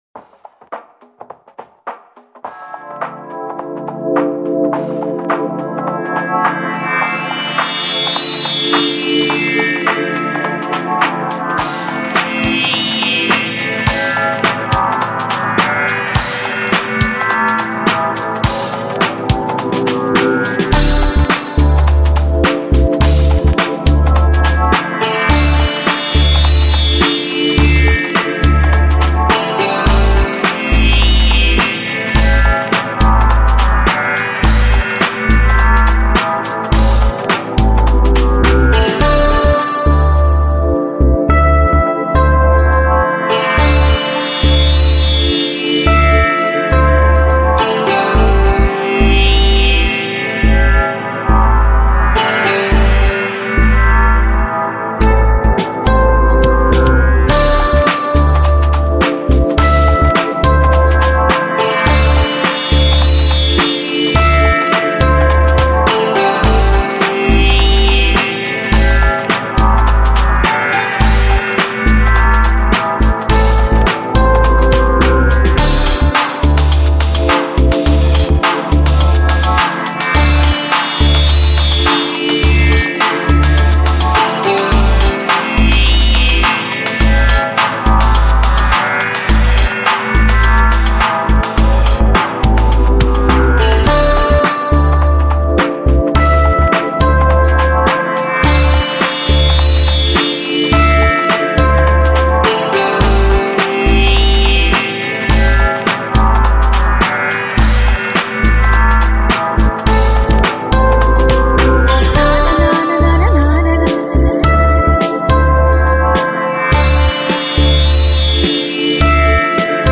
音乐类型：Lo-Fi/Lounge/Chill Out/Downtempo